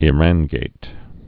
(ĭ-răngāt, ĭ-rän-, ī-răn-)